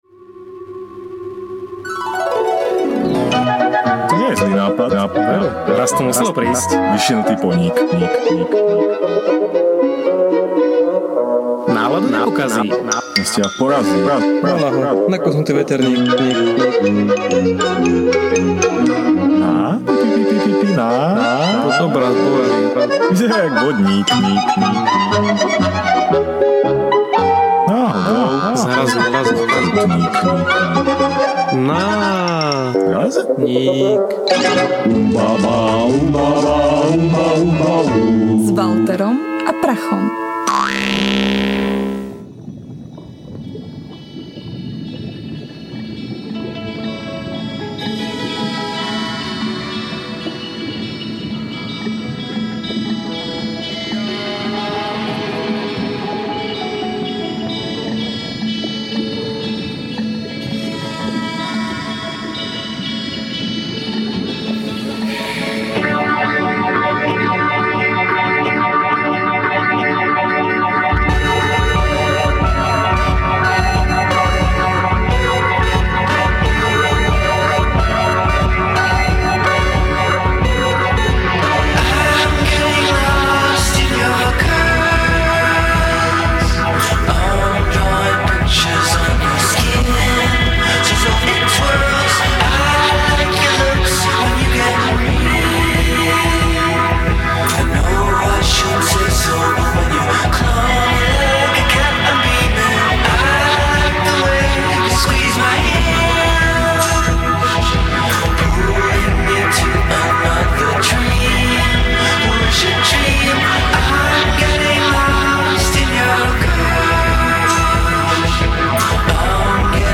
NÁRAZNÍK #10 / Pohnutia - Podcast NÁRAZNÍK / Ukecaná štvrtková relácia rádia TLIS - Slovenské podcasty